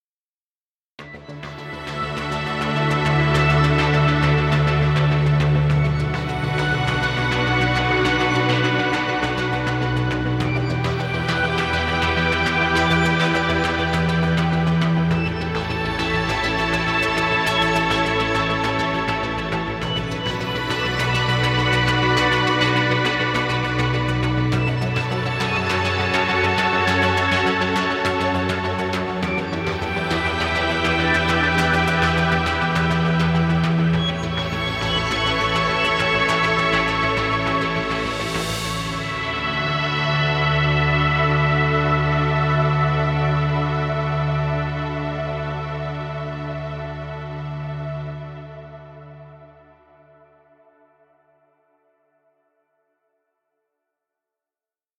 Ambient music.